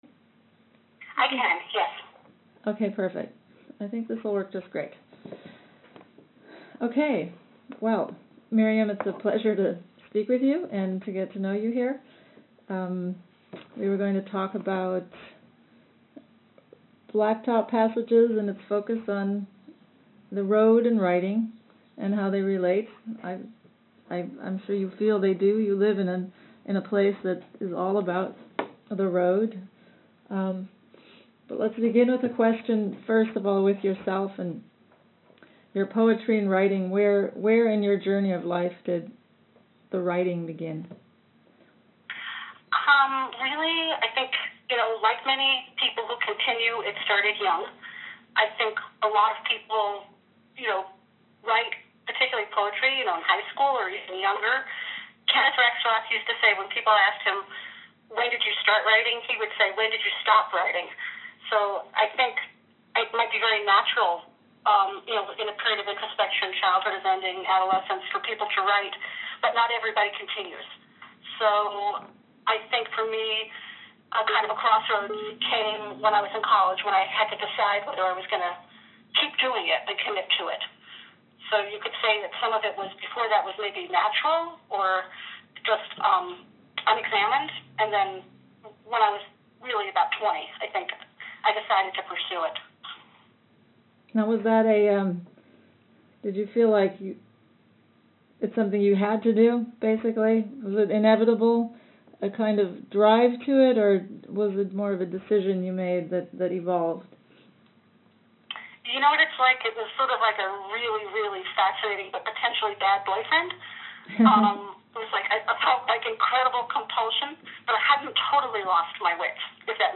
interviews – Blacktop Passages